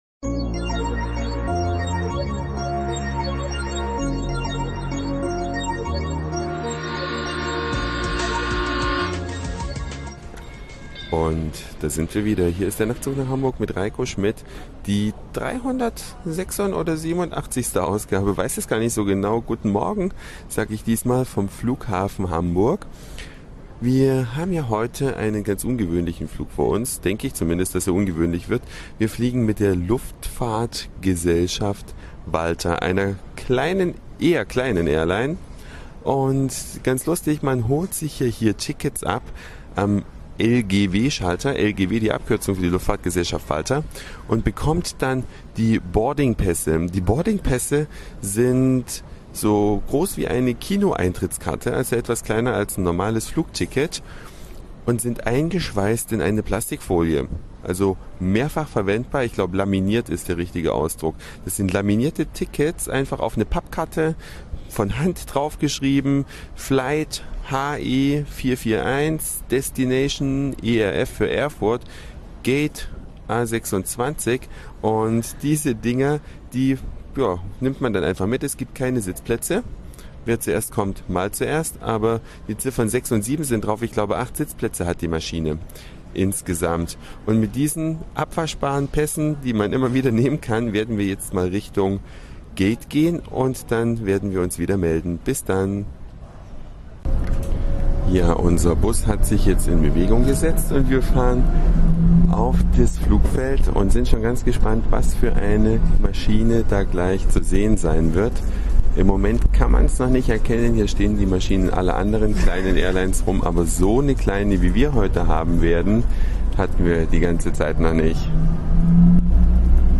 Die Nebengeräusche ließen sich bei der Lautstärke im Flieger nicht vermeiden. Nach der Landung gibts noch ein kurzes Gespräch mit dem Piloten und es beginnt schon die Vorfreude auf den Rückflug...